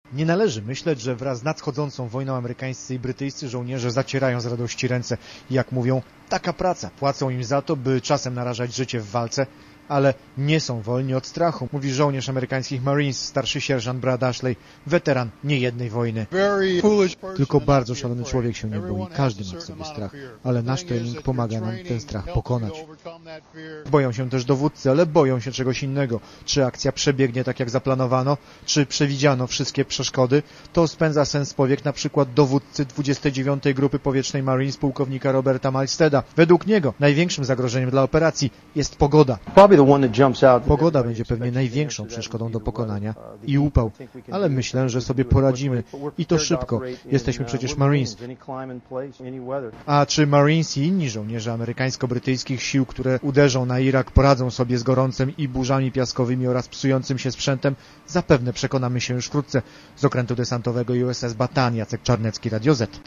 001_marines_o_strachu!!s.mp3